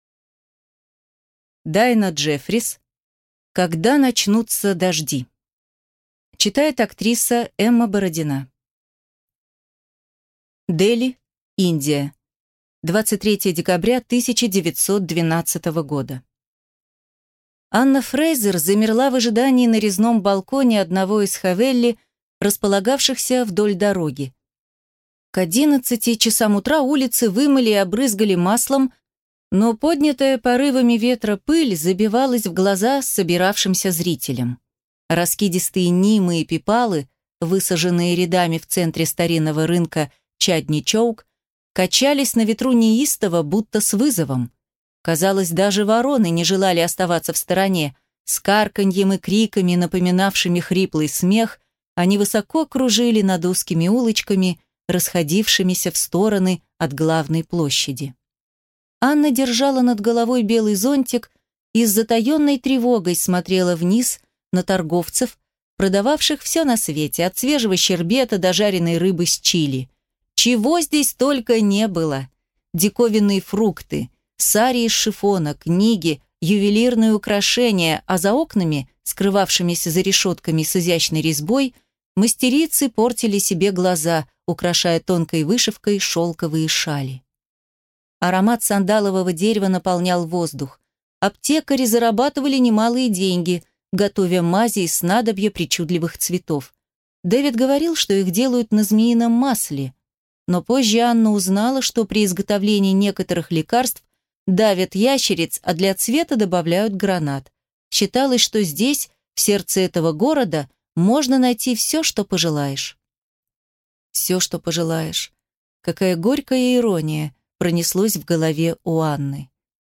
Аудиокнига Когда начнутся дожди | Библиотека аудиокниг